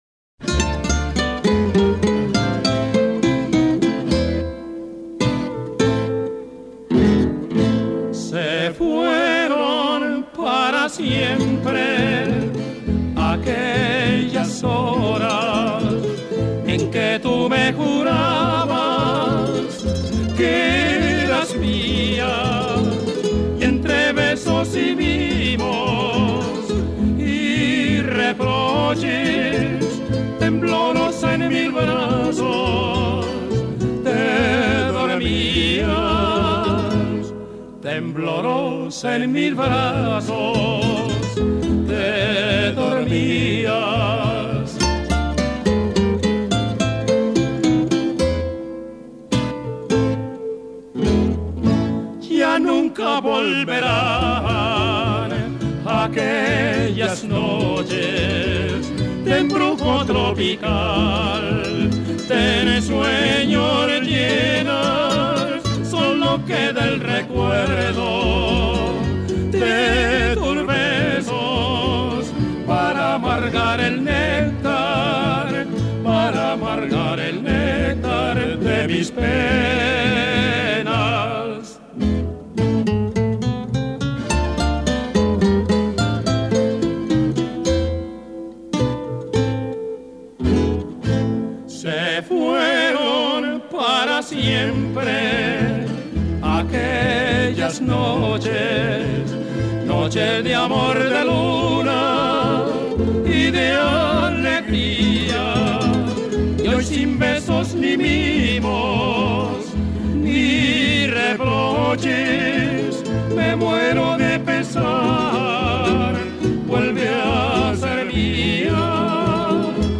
Colombiana